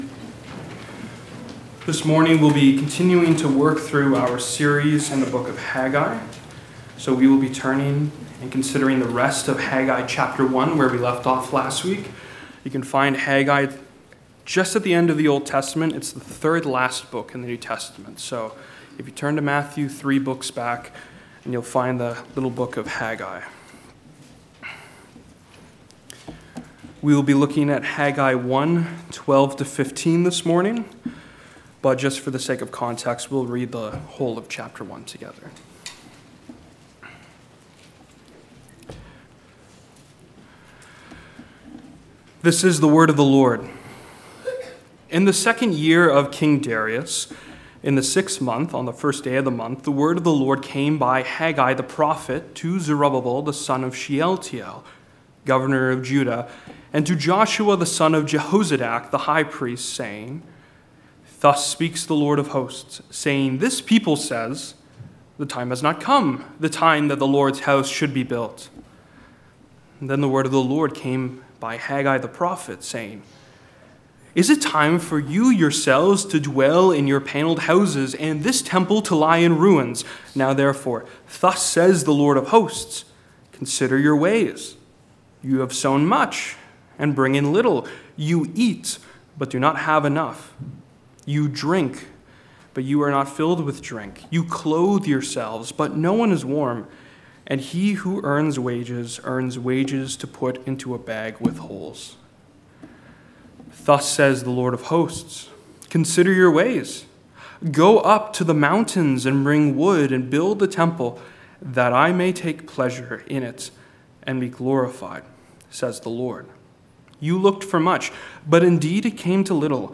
Haggai Passage: Haggai 1:12-15 Service Type: Sunday Morning God makes His presence a joy « Immanuel will save His people Jesus said